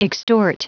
Vous êtes ici : Cours d'anglais > Outils | Audio/Vidéo > Lire un mot à haute voix > Lire le mot extort
Prononciation du mot : extort